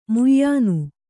♪ muyyānu